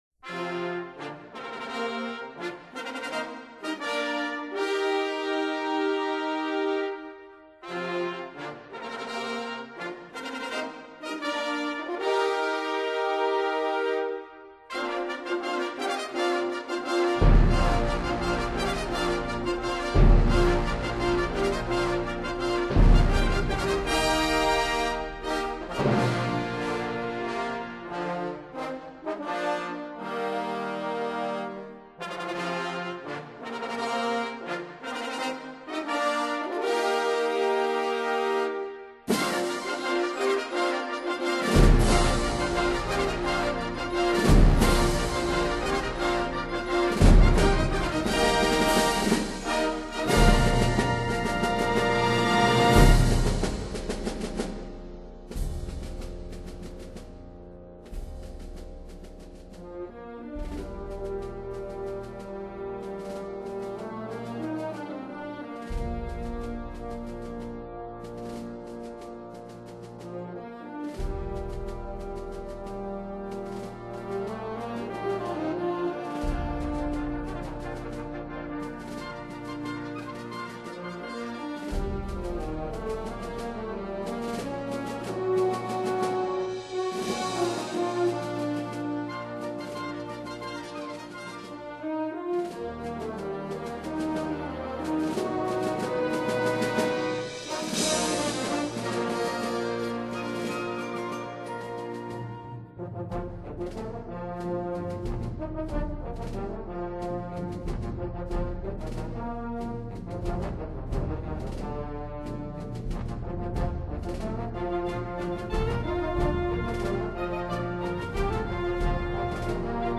音樂類型: 管弦樂